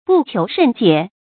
注音：ㄅㄨˋ ㄑㄧㄡˊ ㄕㄣˋ ㄐㄧㄝ ˇ
讀音讀法：